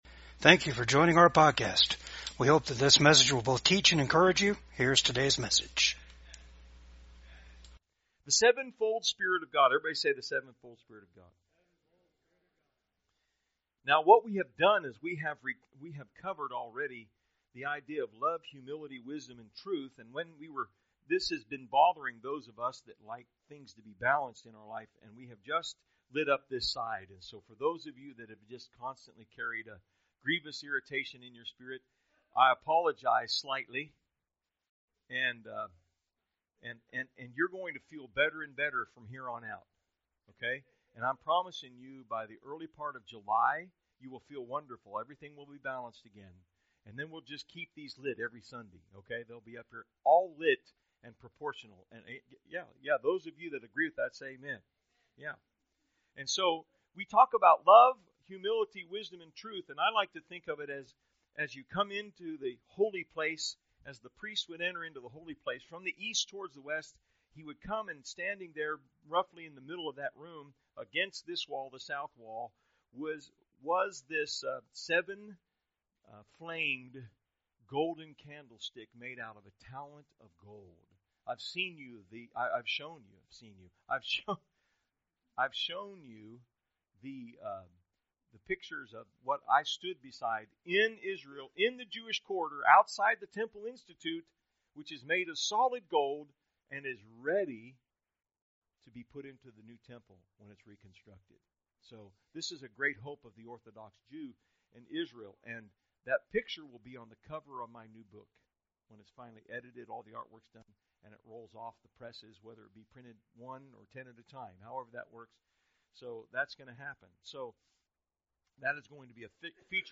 John 1:17 Service Type: VCAG SUNDAY SERVICE THE SPIRIT OF GRACE INVOLVES LOVE